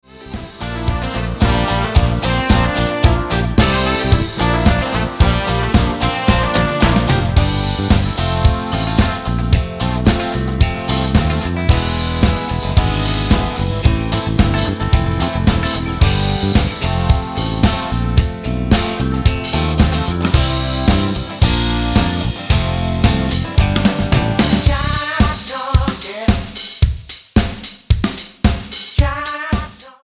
Tonart:C mit Chor